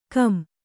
♪ kam or kan or kaṇ